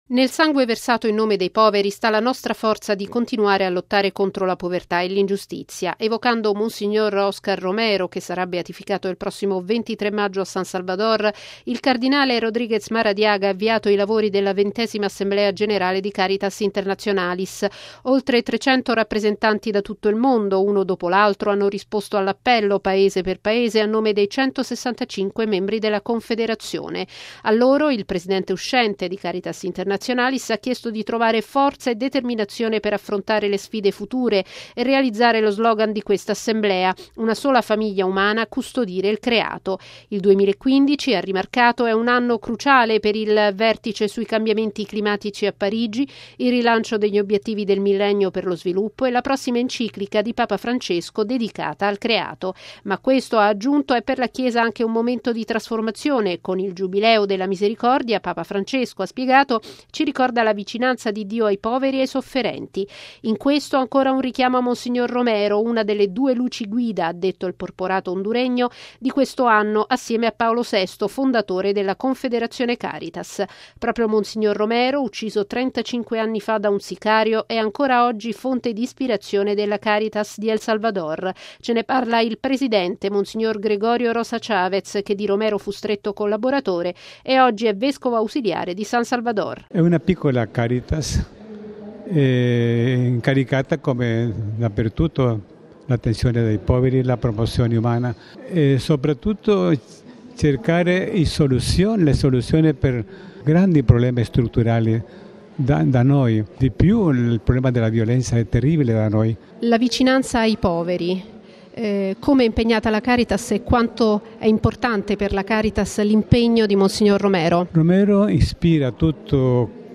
Sono entrati nel vivo a Roma i lavori della 20.ma Assemblea generale di Caritas Internationalis, sul tema "Una sola famiglia umana, custodire il Creato", aperta ieri dalla Messa di Papa Francesco in San Pietro. Stamani, l’intervento del cardinale Óscar Andrés Rodríguez Maradiaga, presidente della confederazione Caritas. Il servizio